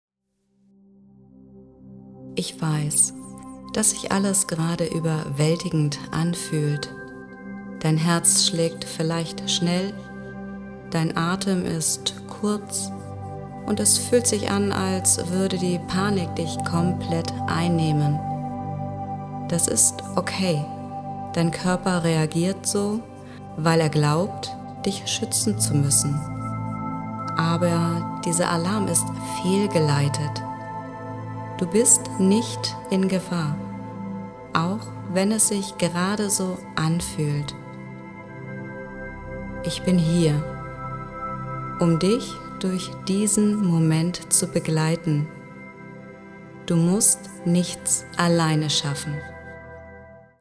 Stell Dir vor, Du hättest eine beruhigende, vertraute Begleitung immer griffbereit – eine professionelle Stimme, die Dich genau in dem Moment unterstützt, wenn die Welt zu viel wird.